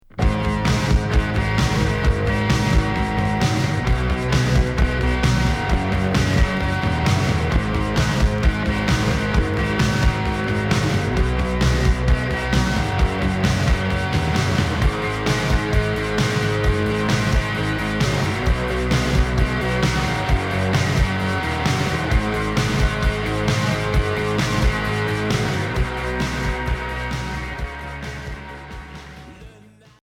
Rock Premier 45t.